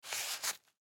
Звуки кошелька
Звук доставания кошелька из кармана штанов